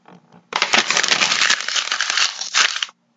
声音 " 皱纹纸
描述：纸张的声音被压成了一个球
Tag: 揉碎 垃圾桶 起皱 起皱 起皱纸 噪音 压皱